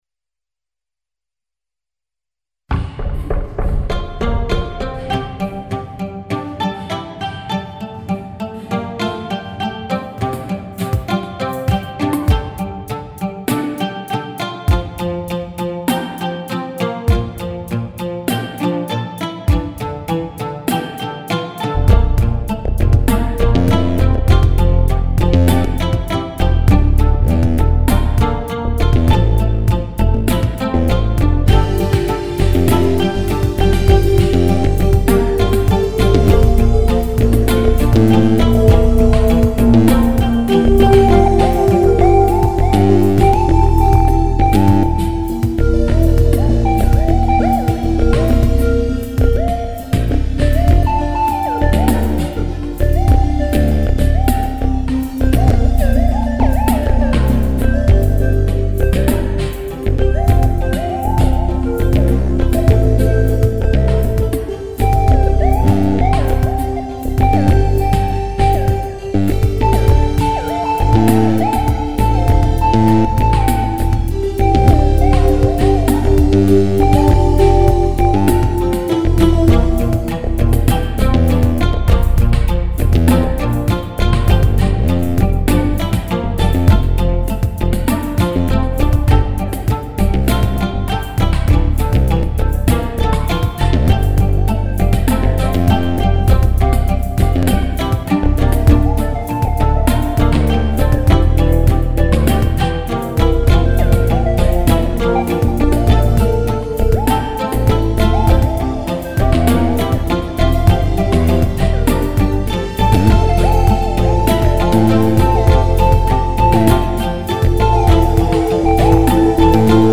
musique,  musique concrète,ambiance, fractale, son, bruit, samples, mp3, streaming
Martin ( --) La(A) - A Bb C D E G 96
Plugins :  Font12! , Evm Bass Line , MDA EPiano , Synth1 ,
Drum loop : Arythm
(Boiteux-04) (Coef : 4.27)